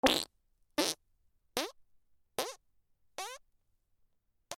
おなら
/ J｜フォーリー(布ずれ・動作) / J-25 ｜おなら・大便